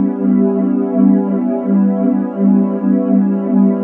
cch_synth_fun_125_Bm.wav